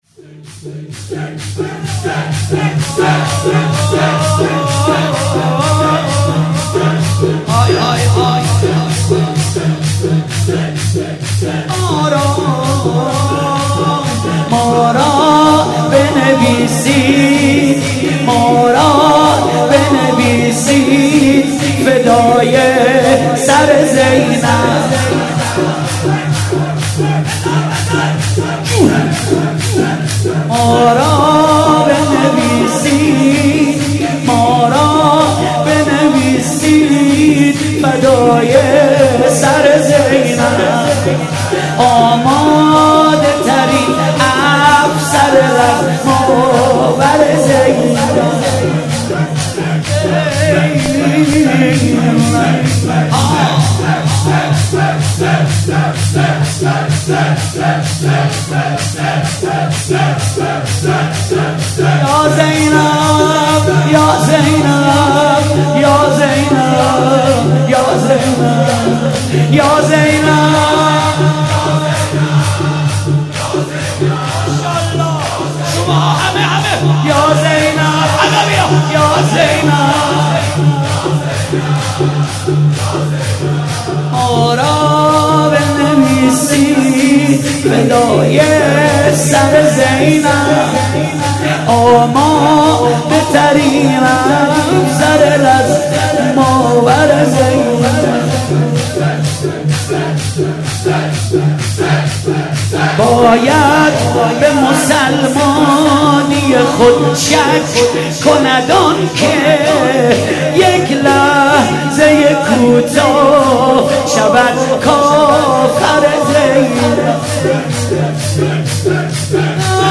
مناسبت : شب هشتم محرم
قالب : شور